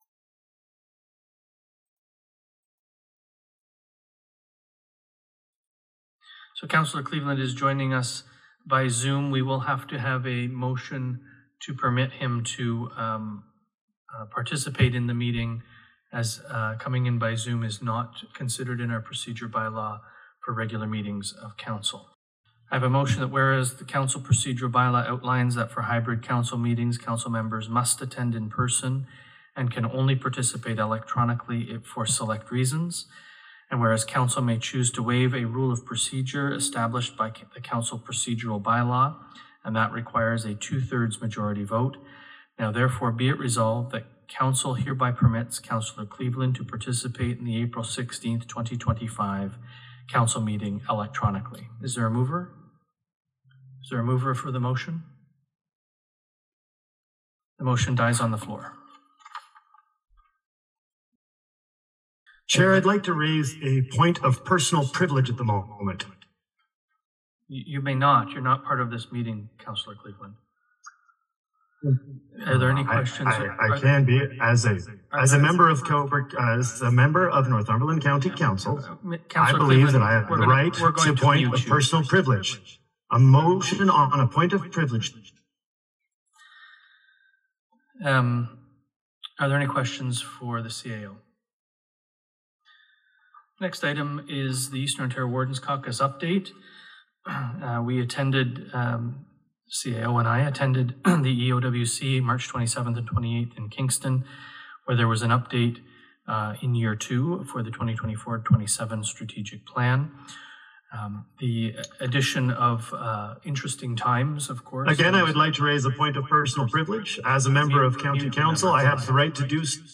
Cleveland’s microphone was muted for the meeting as he listened in, but could not participate. At two points, his microphone became live.